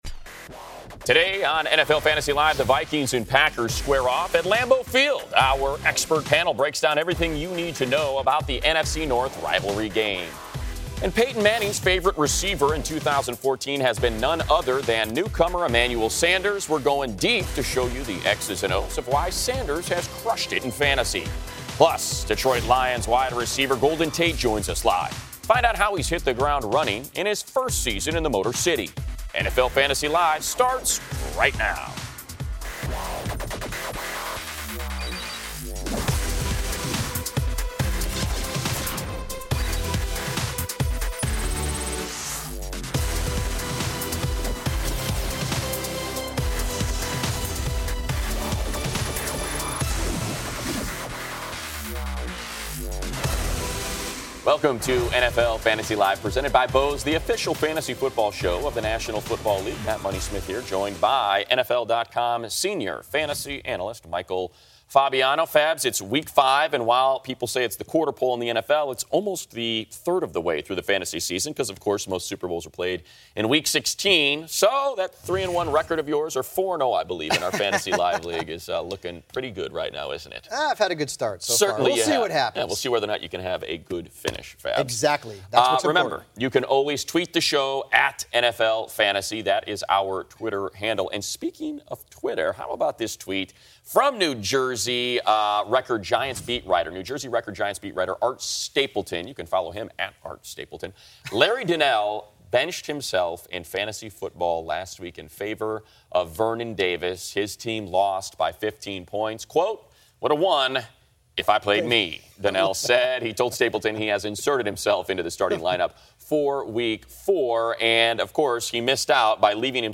Detroit Lions wide receiver Golden Tate joins us live. Find out how he's fitting into the Lions' offense and we ask him about his fantasy team.